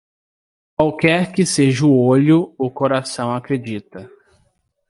Pronounced as (IPA) /ˈo.ʎu/